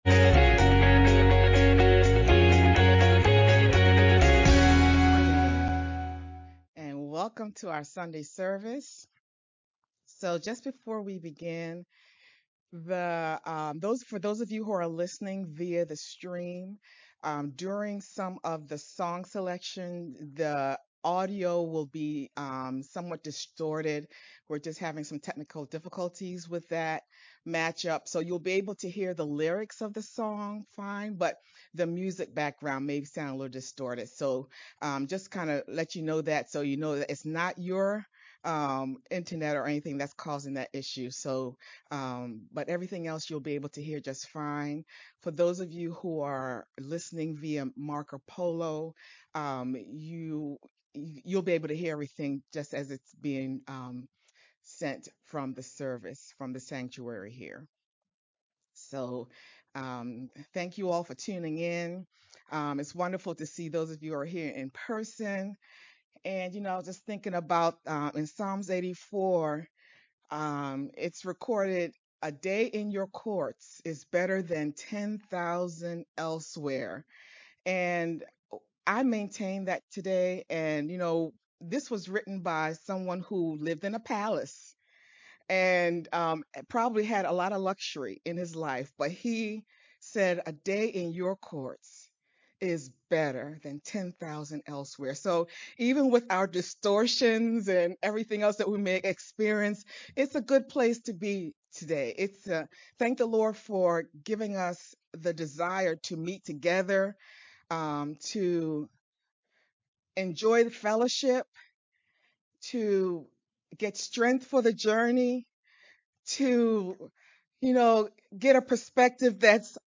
VBCC-Sermon-June10th-edited-Mp3-CD.mp3